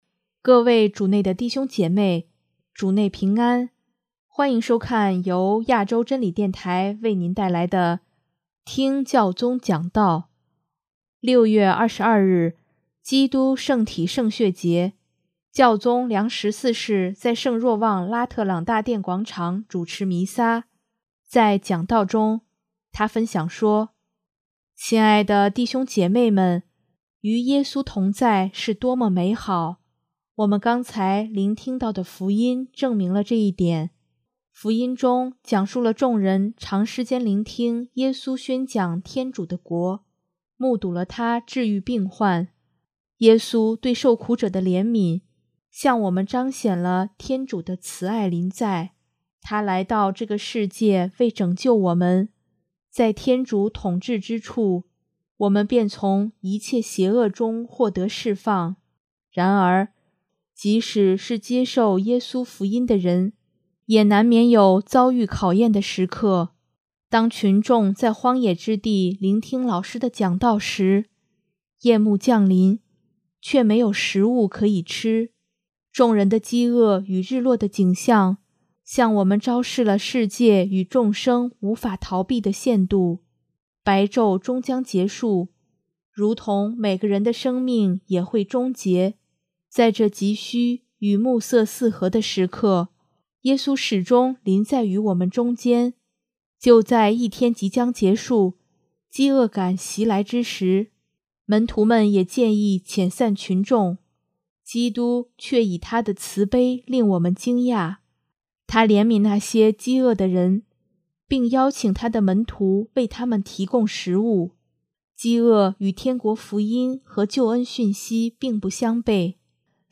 6月22日，基督圣体圣血节，教宗良十四世在圣若望拉特朗大殿广场主持弥撒，在讲道中，他分享说：